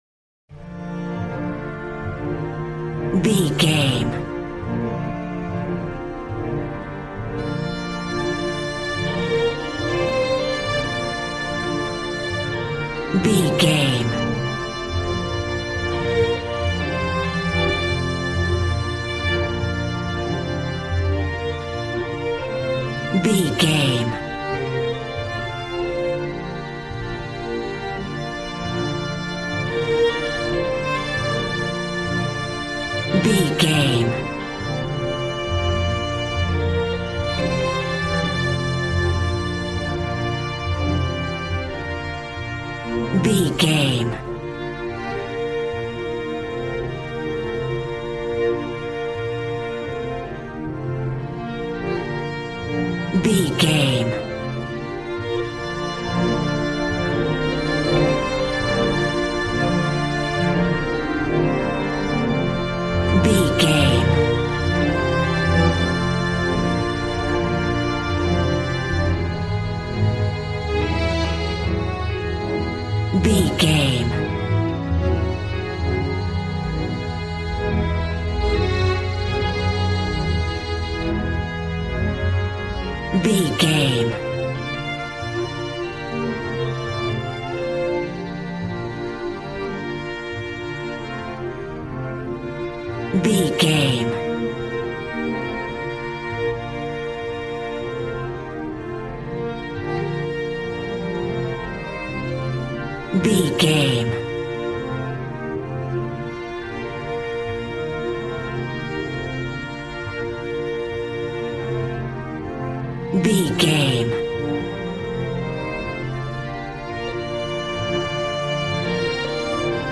Aeolian/Minor
joyful
conga